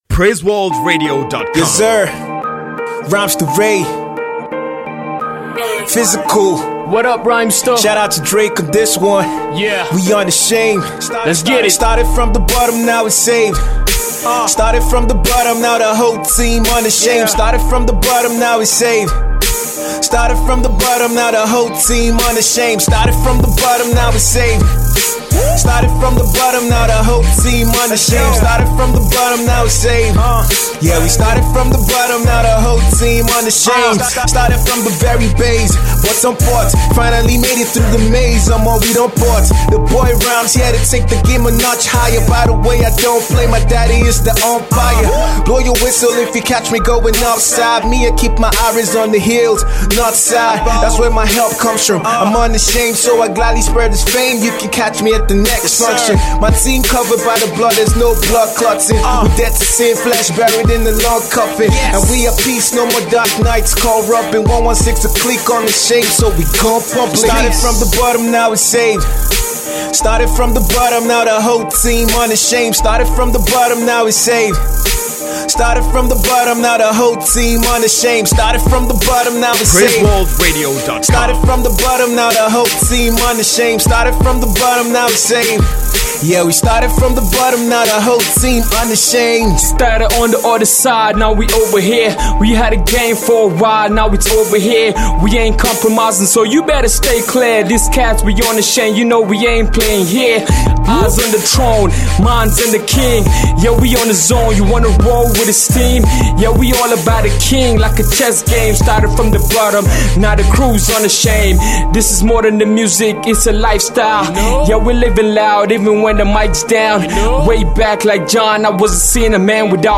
uber-fresh hip hop tune